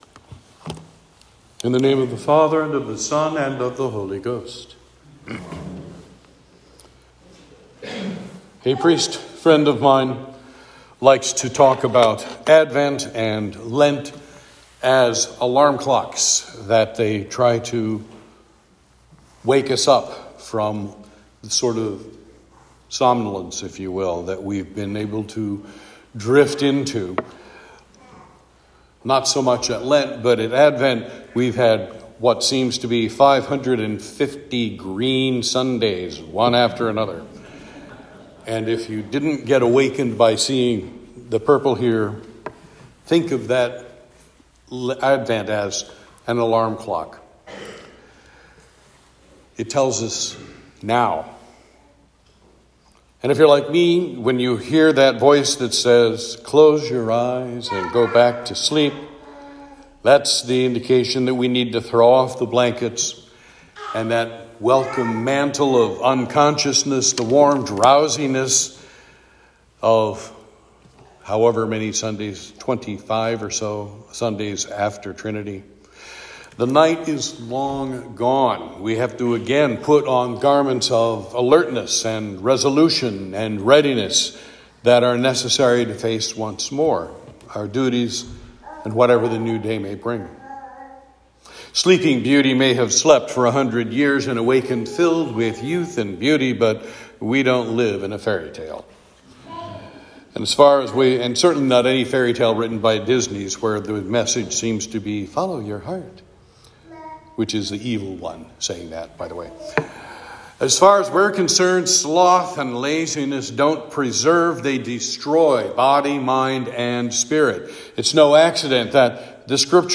Sermon for Advent 1